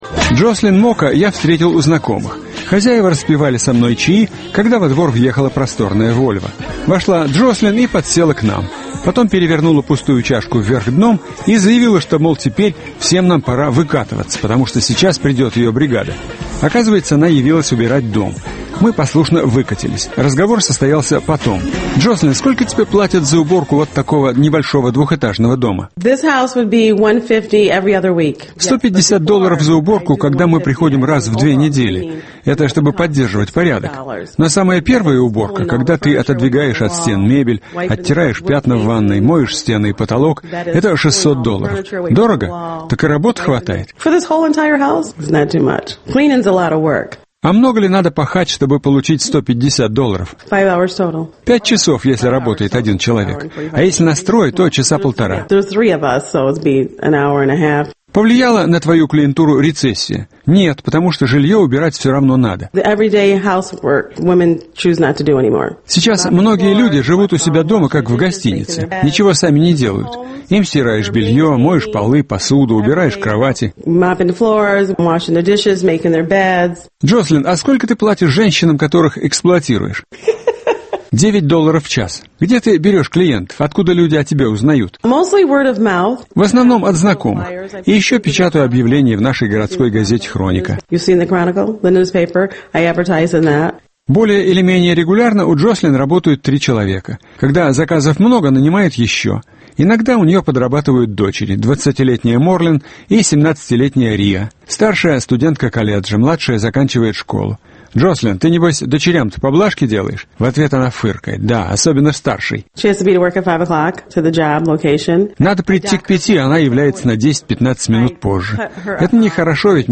Радиоочерк.